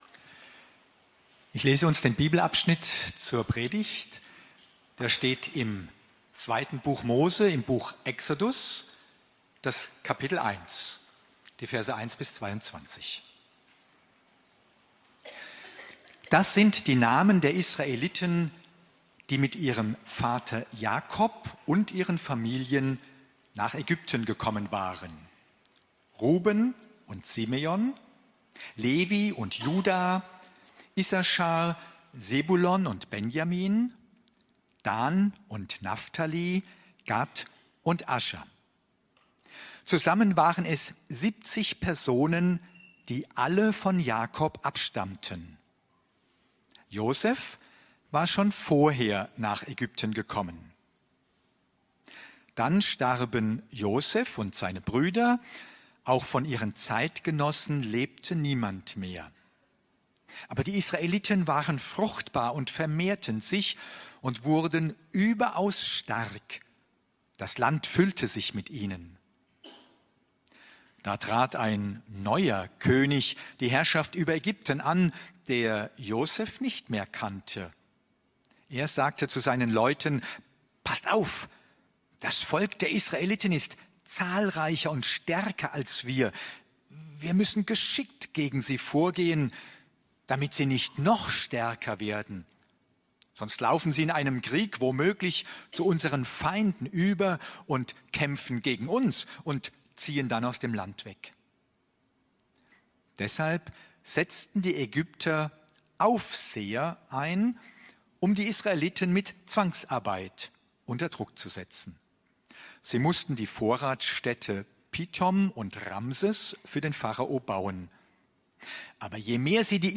Predigten – Er-lebt.